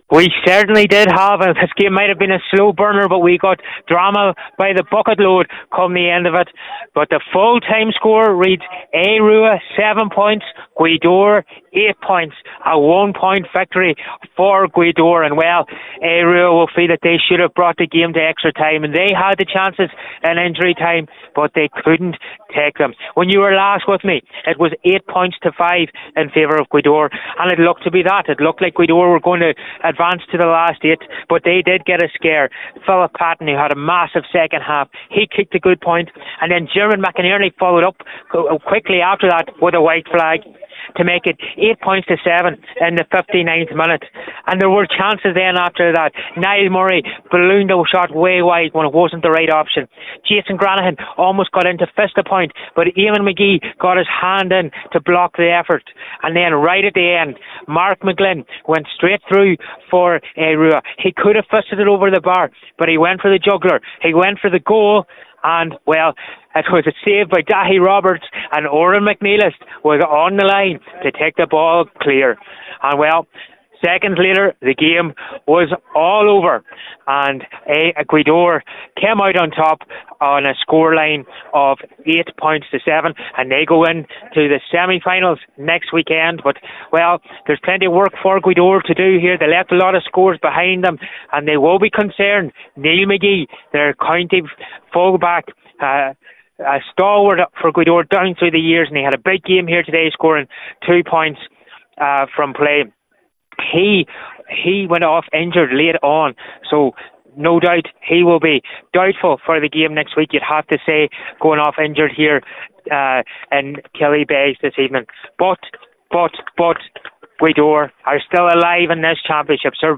FT Report and Reaction: Gaoth Dobhair squeeze by Aodh Ruadh in Donegal SFC quarter final